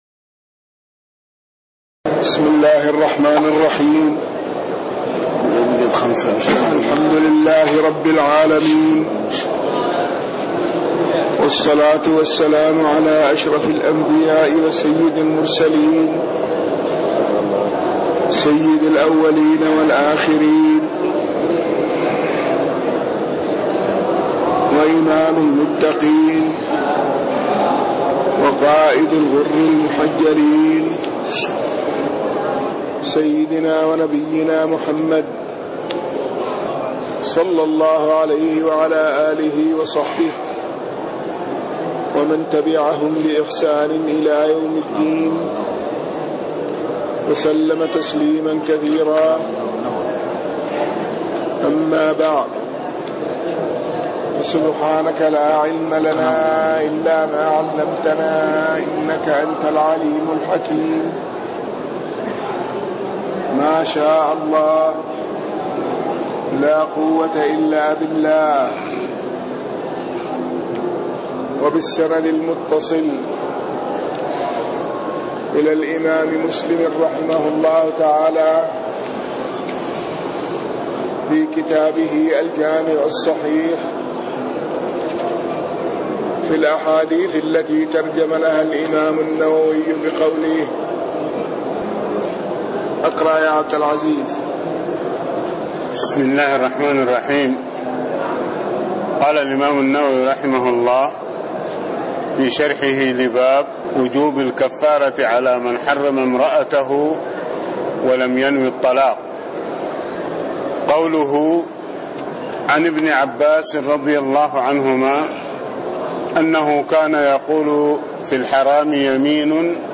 تاريخ النشر ٢١ شعبان ١٤٣٤ هـ المكان: المسجد النبوي الشيخ